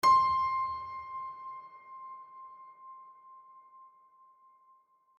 piano-sounds-dev
HardPiano
c5.mp3